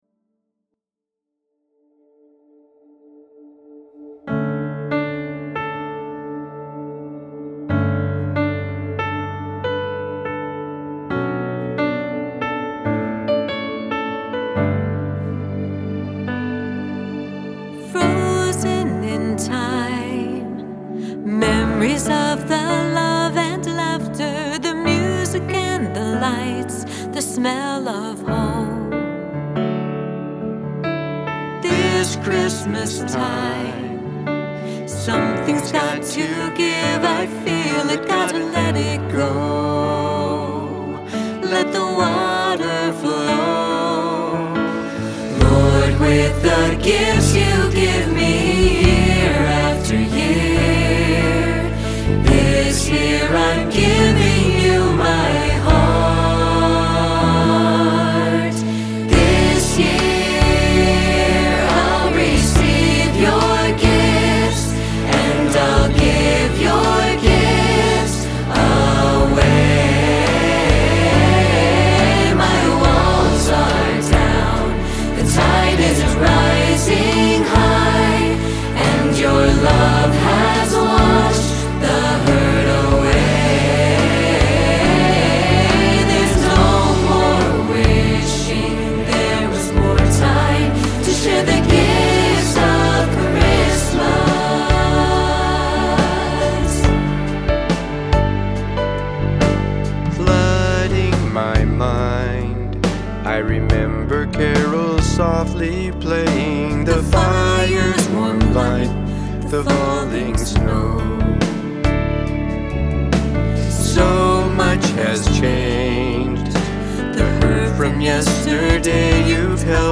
Written in piano/vocal/parts
Original Recording